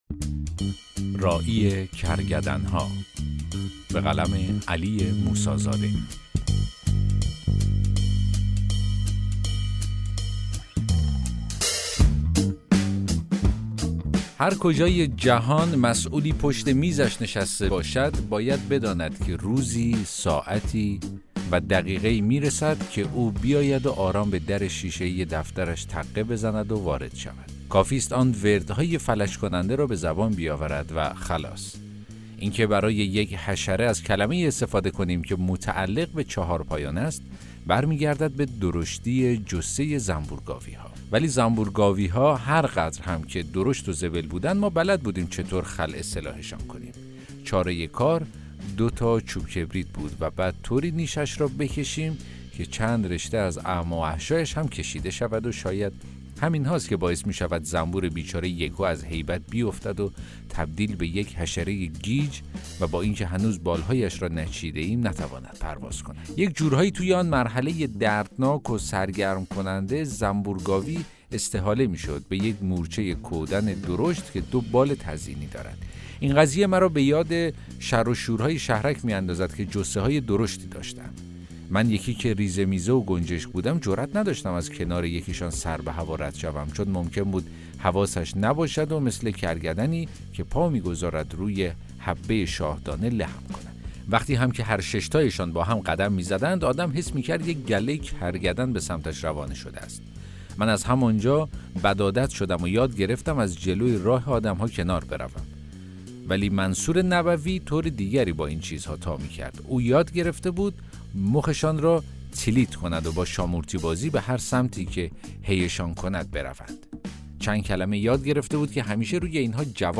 داستان صوتی: راعی کرگدن ها